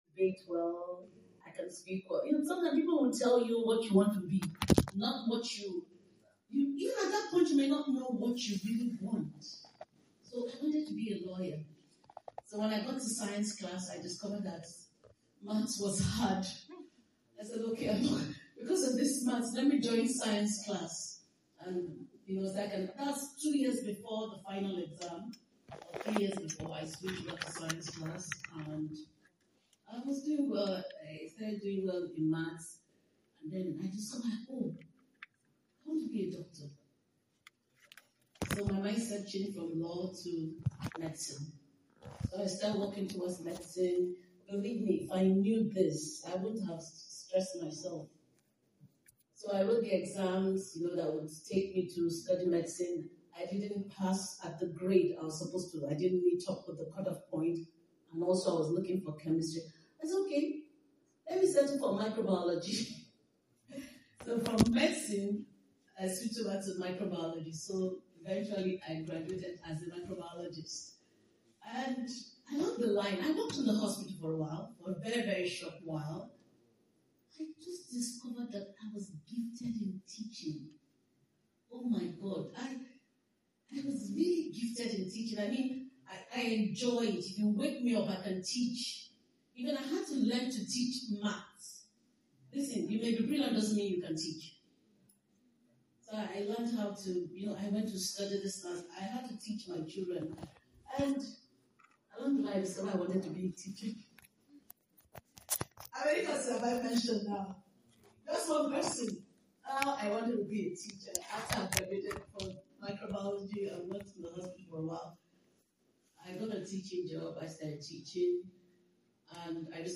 Listen to the complete teachings from the RCCG Youth Program